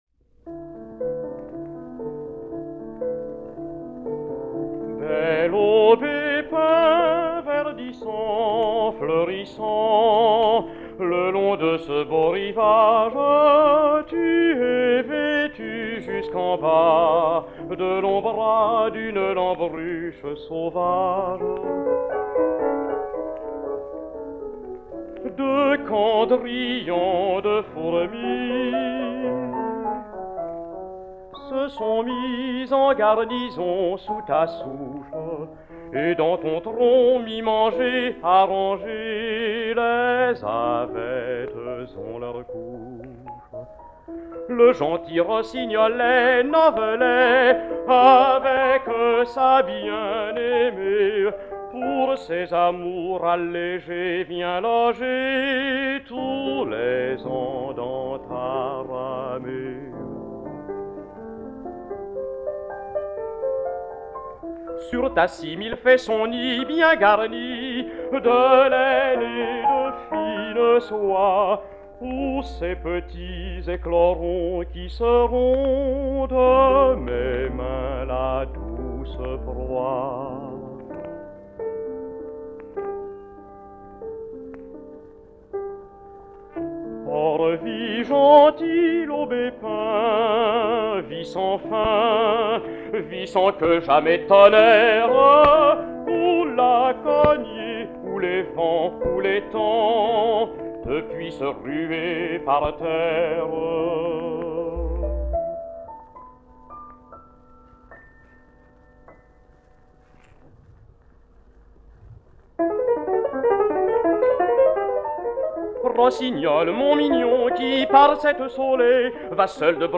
Baritone
piano